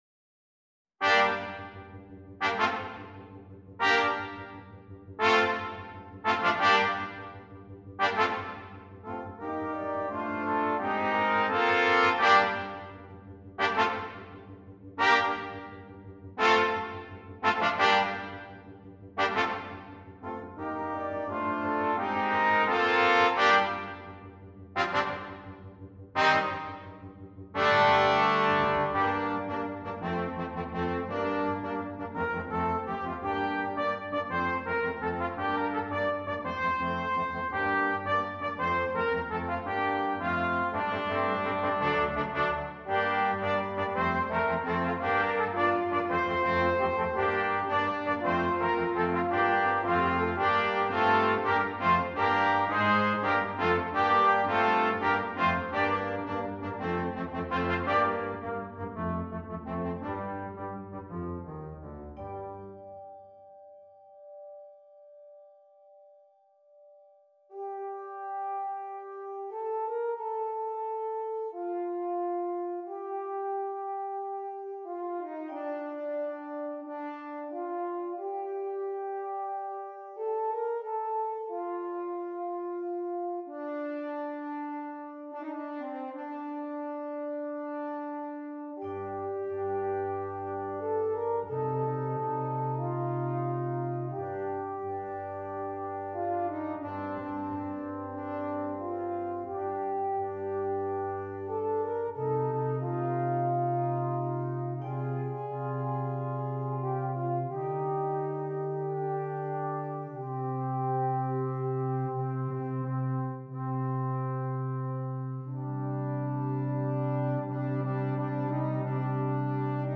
Brass Quintet
driving and enthusiastic.